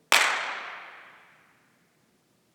File 2 is the best hand-clap of a series extracted from a wave file recording made in the smaller of the two spaces (1/3 of the total ballroom).
Note the lower noise floor compared to File 1.
Audio File 2 – A hand-clap recorded in the smaller space.
Also shown are the 1/1-oct reverb times, computed from the ETC. The room was very quiet so the hand-clap produced a decent RIR.
handclap.wav